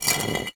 brick-move.wav